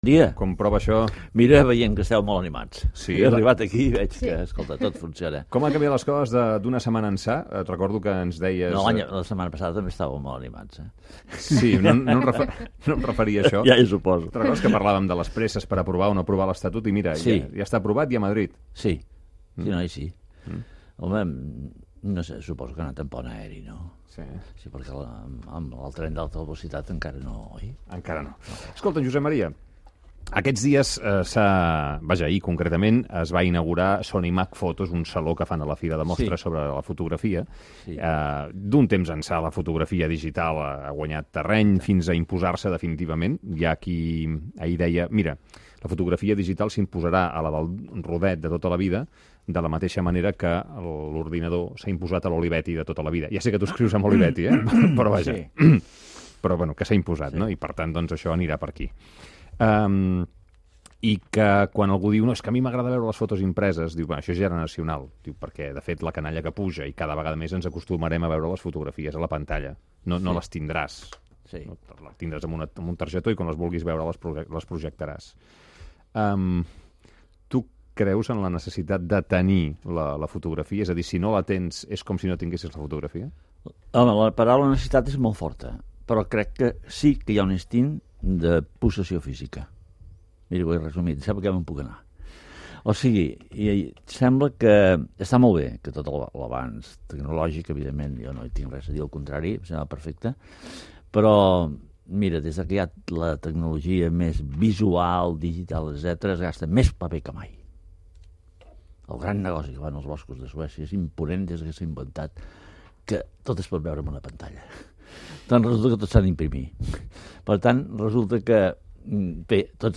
El col·laborador del programa Josep Maria Espinás parla de la fotografia digital i de la necessitat d'imprimir-les en paper, amb motiu de la celebració de Sonimag Foto
Info-entreteniment